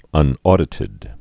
(ŭn-ôdĭ-tĭd)